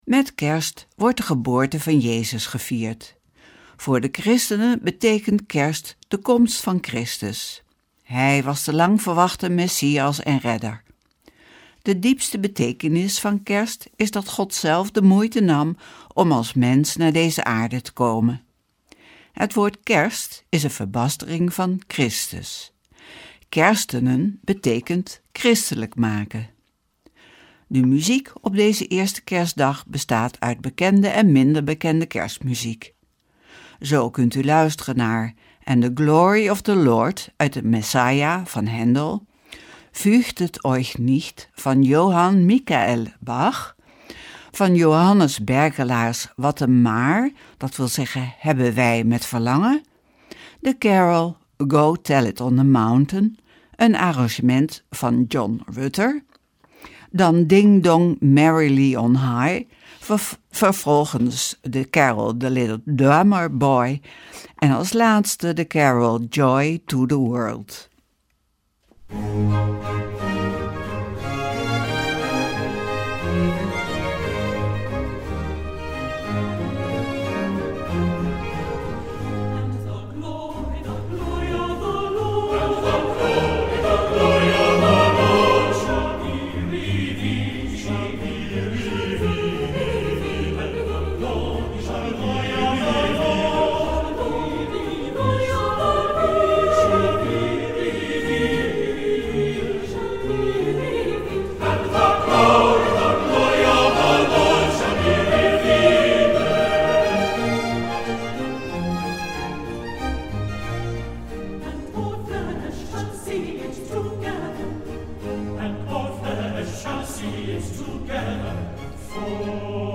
Opening van deze eerste Kerstdag met muziek, rechtstreeks vanuit onze studio.
De muziek op deze eerste kerstdag bestaat uit bekende en minder bekende kerstmuziek.